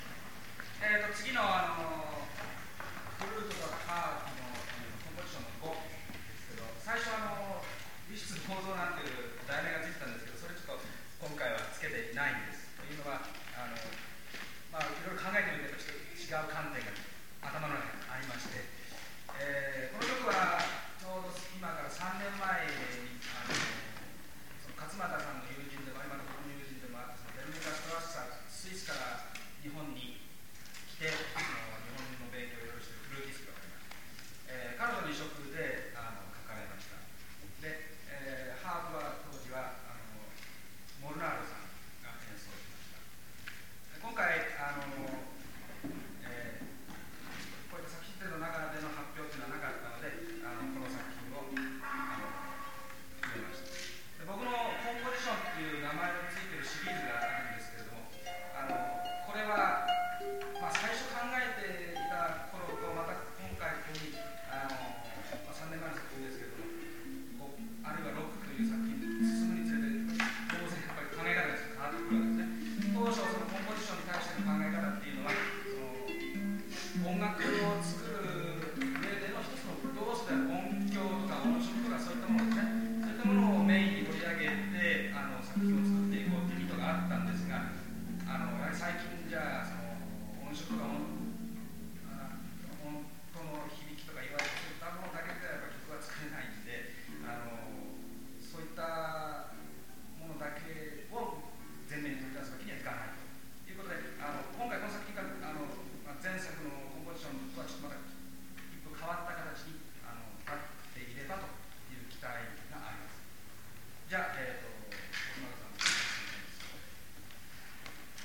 Introductory comments to the performance of Composition V